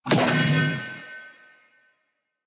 match-join.ogg